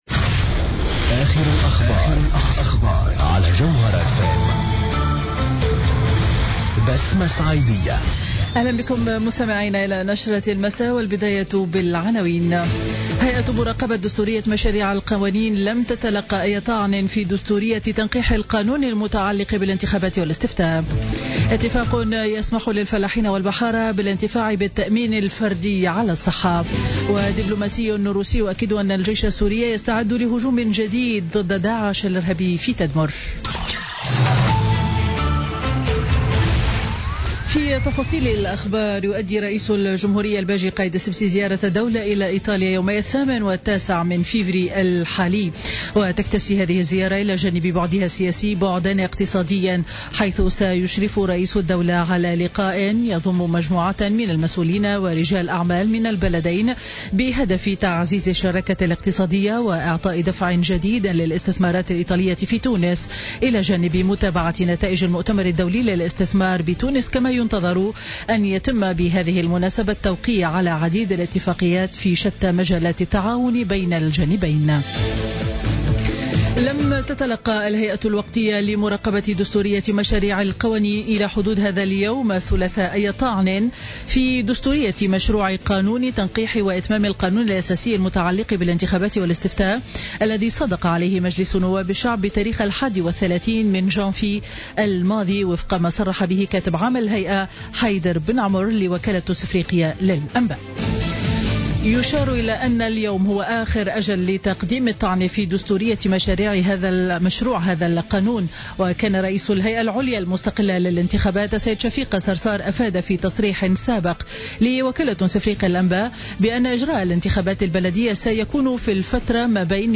نشرة أخبار السابعة مساء ليوم الثلاثاء 7 فيفري 2017